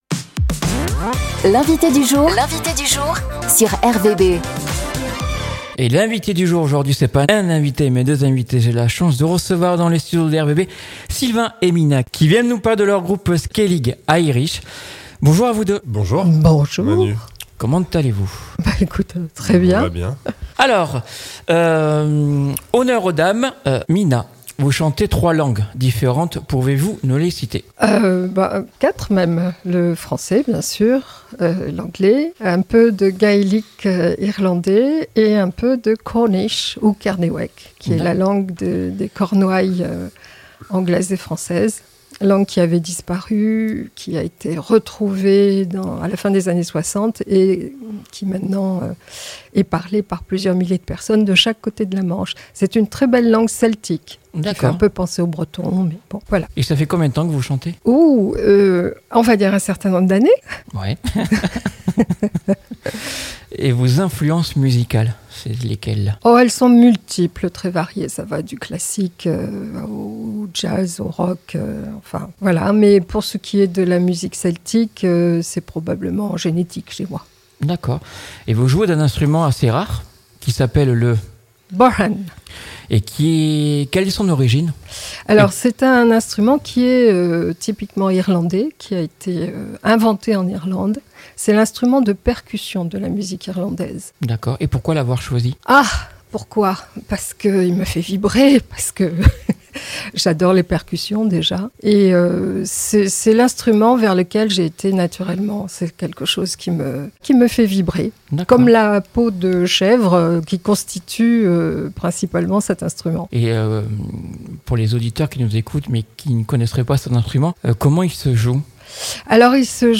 Skellig Irish groupe de musique celtique musiques et chants d'Irlande d'Ecosse et d'ailleurs a rendu visite à RVB.